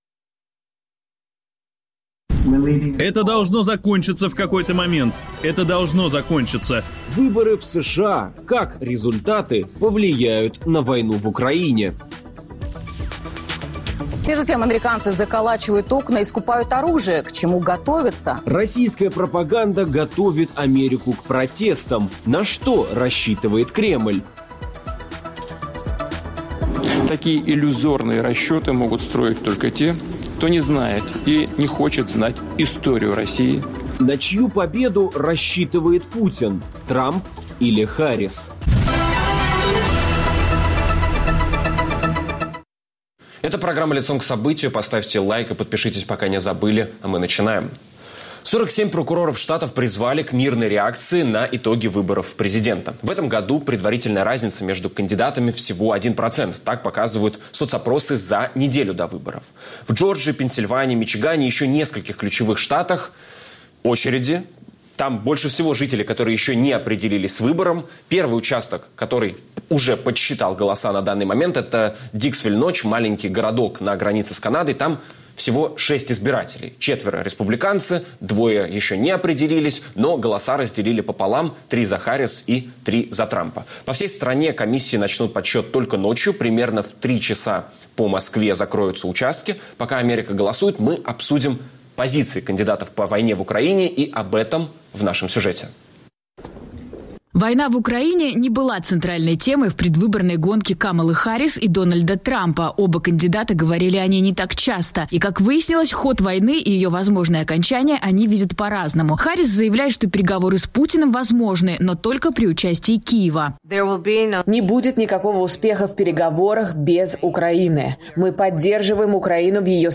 Каким видят окончание войны Харрис и Трамп? И какой президент США нужен Путину? Попробуем разобраться с политологом